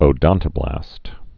(ō-dŏntə-blăst)